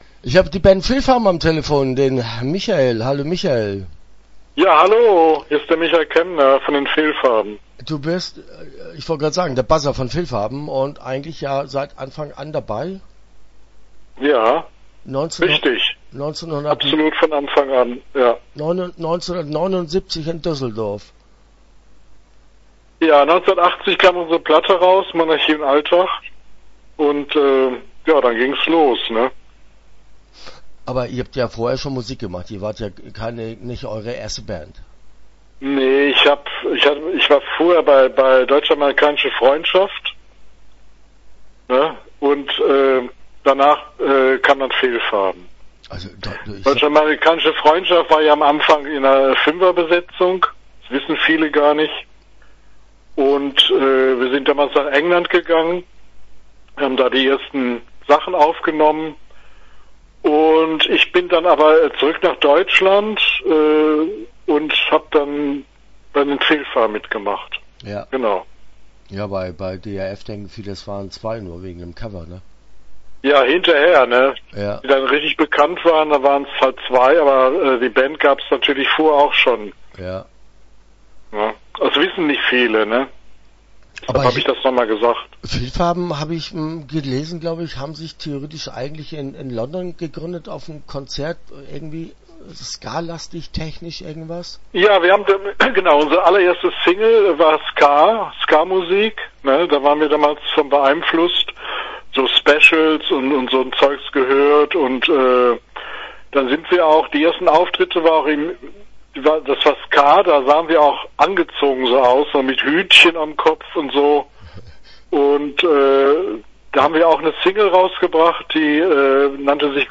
Fehlfarben - Interview Teil 1 (12:08)